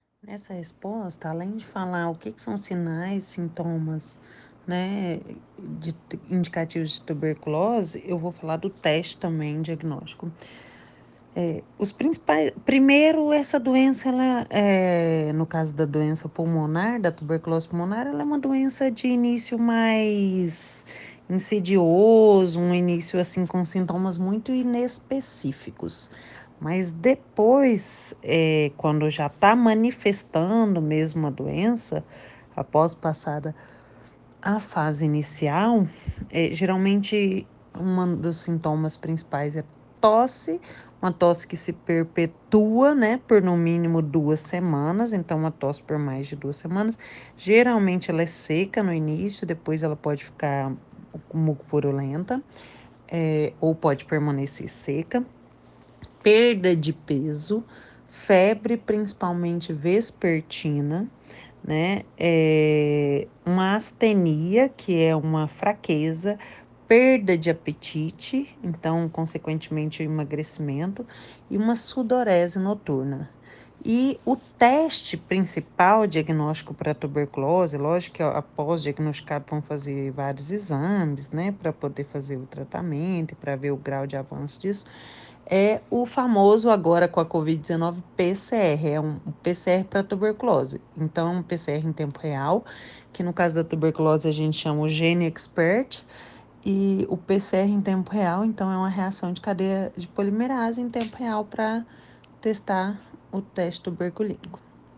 Especialista explica como se prevenir e tratar da doença que é considerada um problema de saúde pública